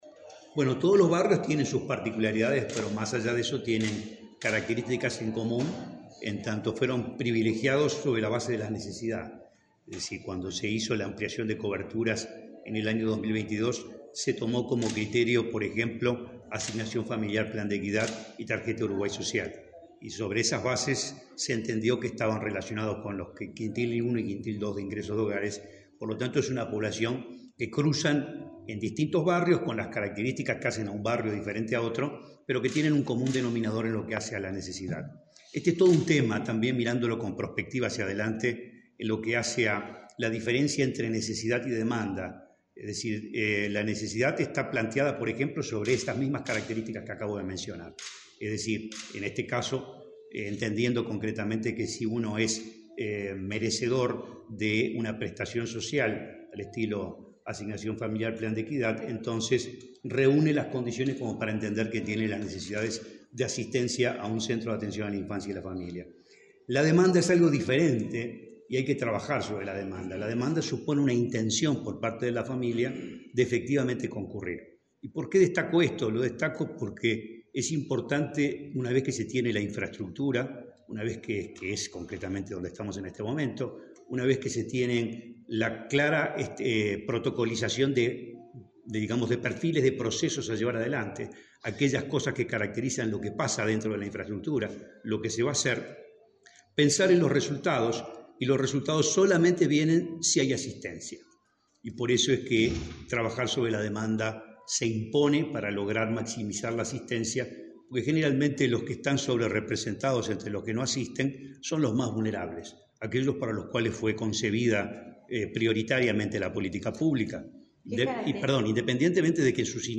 Declaraciones del presidente del INAU, Guillermo Fosatti
El presidente del Instituto del Niño y el Adolescente del Uruguay (INAU), Guillermo Fosatti, dialogó con la prensa luego de participar en la